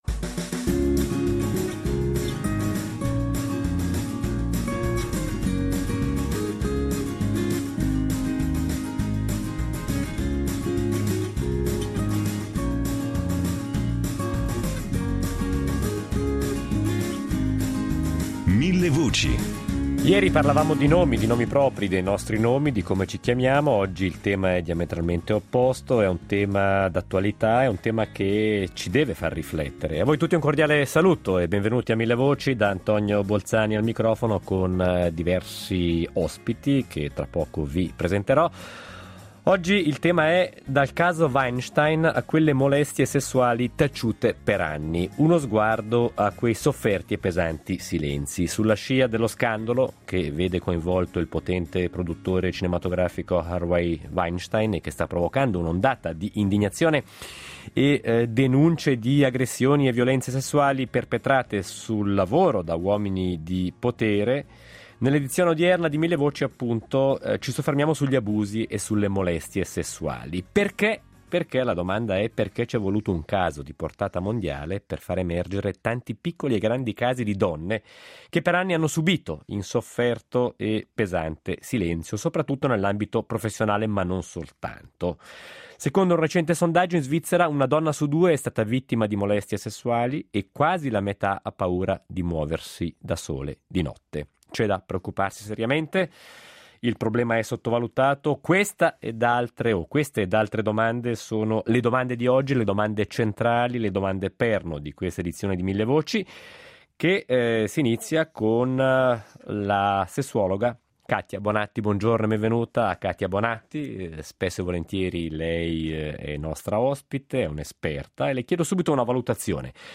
Ne discutiamo con vari ospiti.